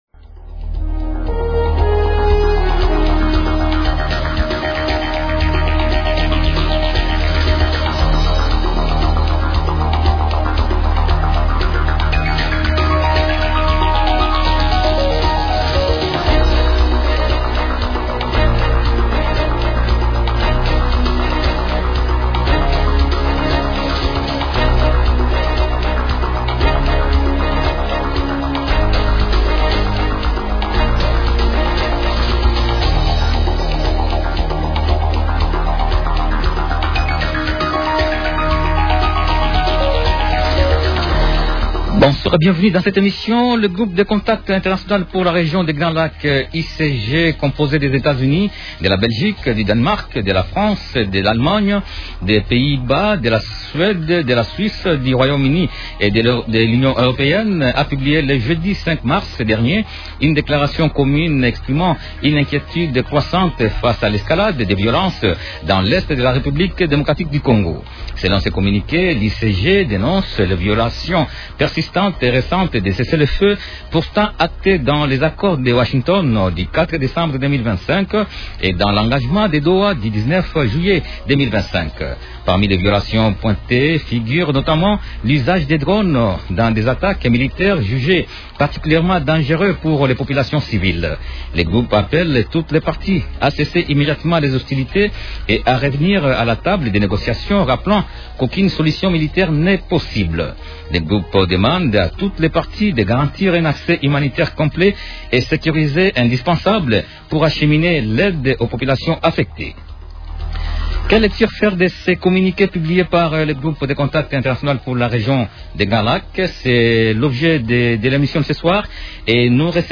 Il est sénateur élu de la Mongala.